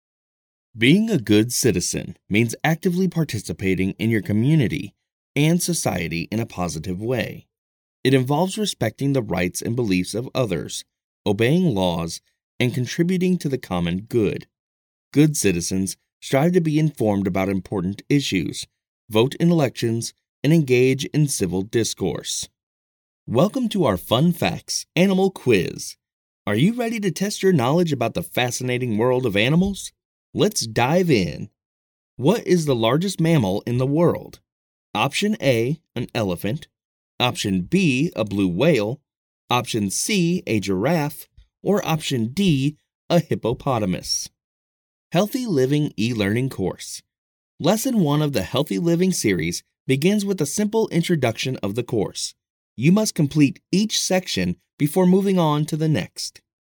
I have worked with some great clients such as WalMart, SNHU, Oil Changers INC, ETC. I bring professional Studio quality sound to you project.
E learning
I specialize in strong, deep, guy next door, conversational, confident, friendly, and professional voice overs. i can provide a free sample if needed.
My experiences in vo include: • e learning • commercial • radio • tv • internet video • telephony • character • audio book • narration I use a rode nt microphone microsoft computer adobe audition daw.
elearning demo.mp3